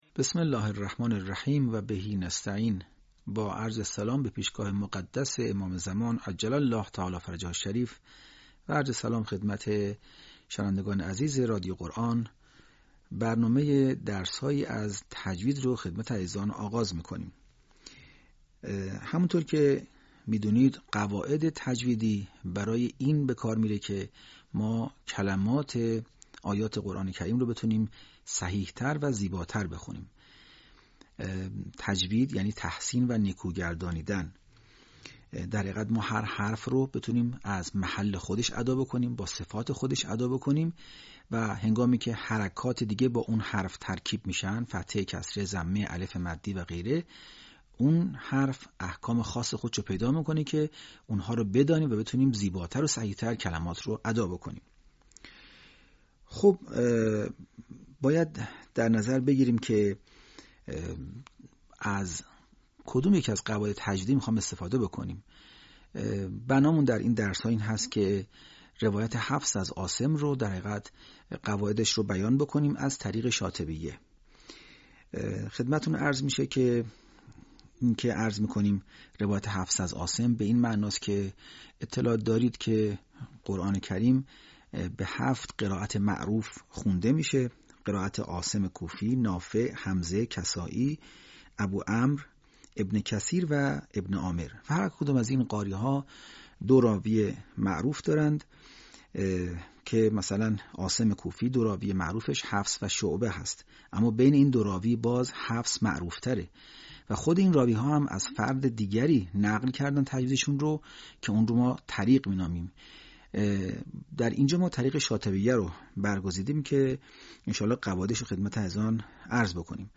آموزش تجوید